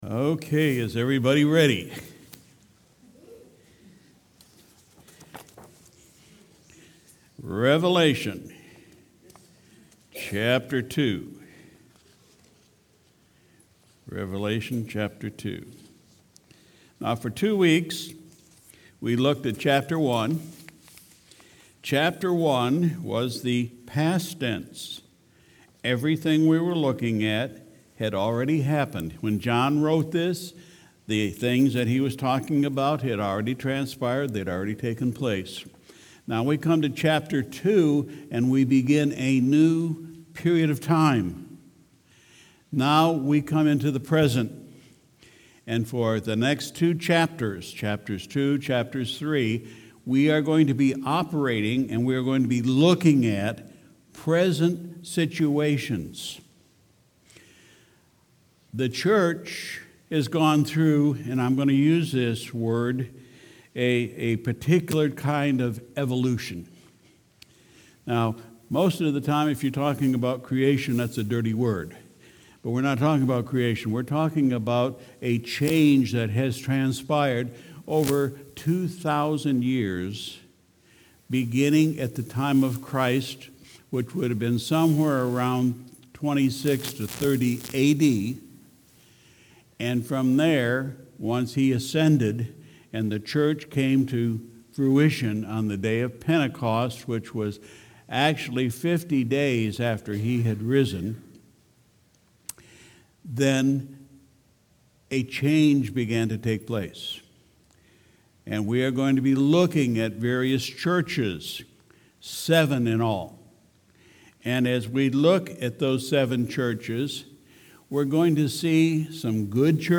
Sunday, September 8, 2019 – Evening Service